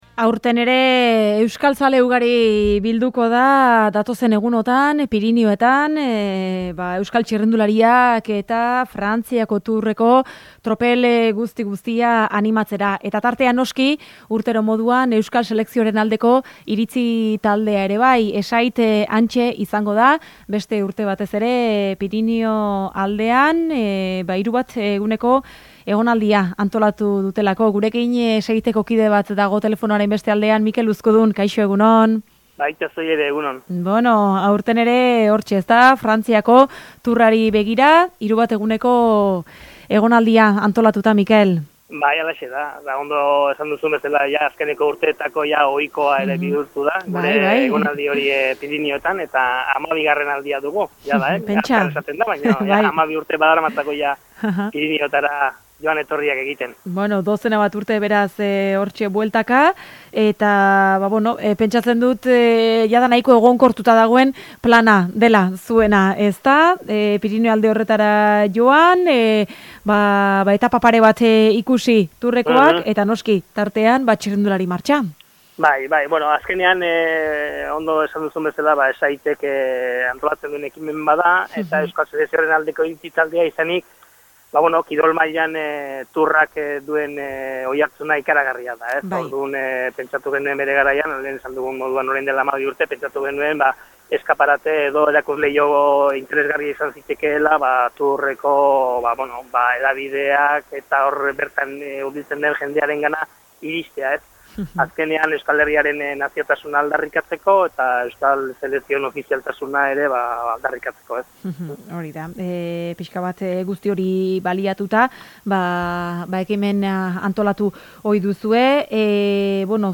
Hilaren 17, 18 eta 19an Pirinioetan izango dira tourreko bi etapa ikusi eta txirrindulari martxa egiteko asmoz, besteak beste. Xehetasun guztiak gure mikrofonoetan eman dizkigute ESAITeko kideek.